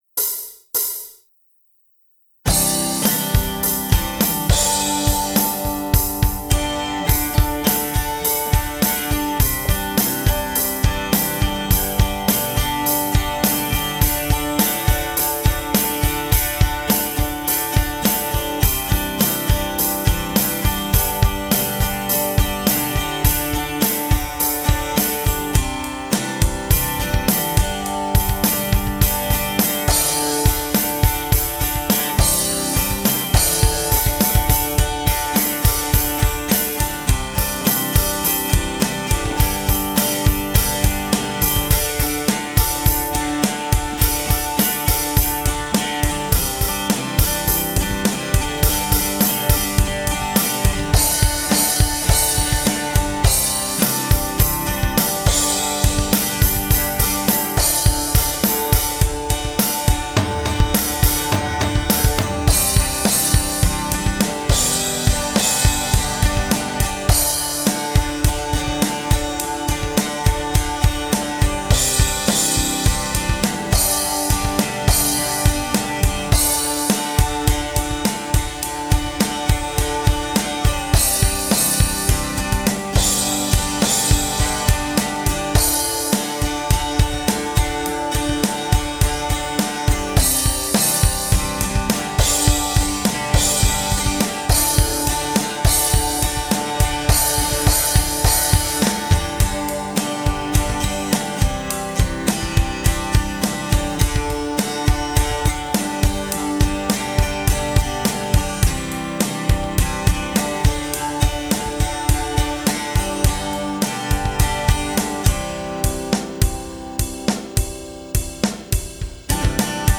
music_smorgasbord_thehoustonpost_acousticguitar.mp3